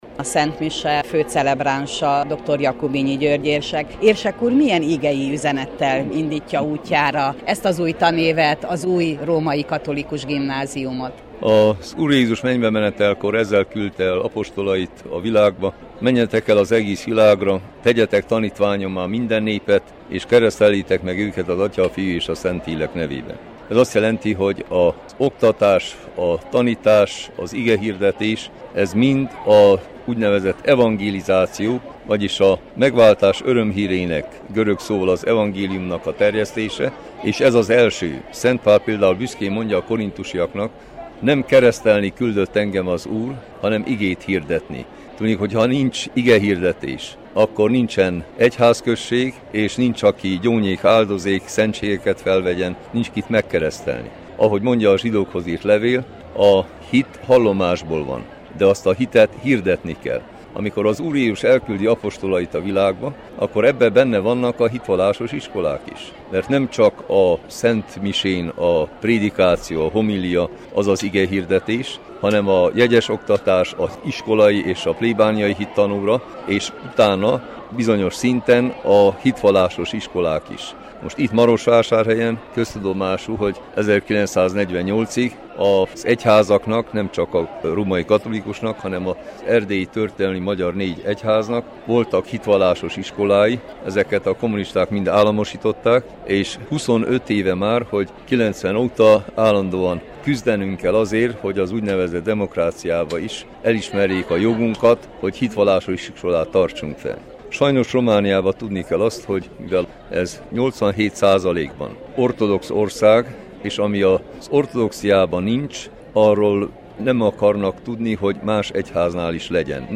Jakubinyi György érsek tartott tanévkezdő szentmisét
Veni Sancte tanévnyitó szentmisét tartottak délelőtt a Keresztelő Szent János plébániatemplomban, melynek főcelebránsa dr. Jakubinyi György érsek, a Gyulafehérvári Főegyházmegye főpásztora volt.